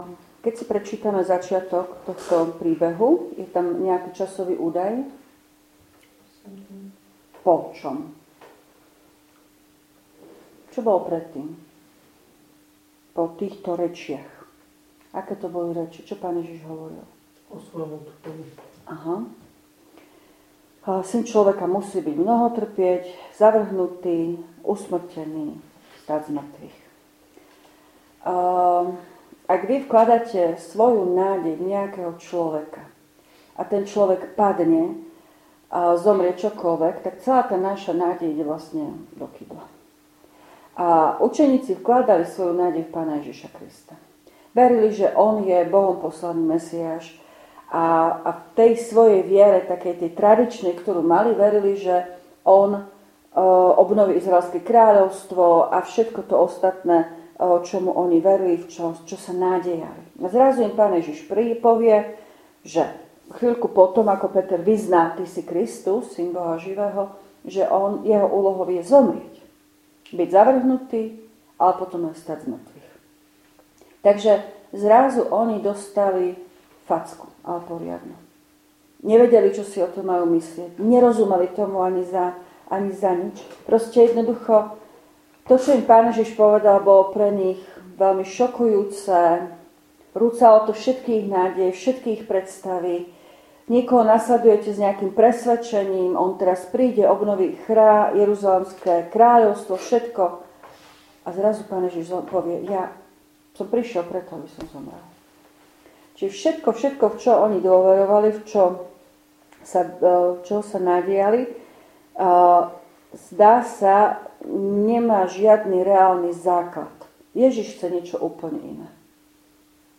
Biblická hodina zo dňa 16.9.2025
V nasledovnom článku si môžete vypočuť zvukový záznam z biblickej hodiny zo dňa 16.9.2025.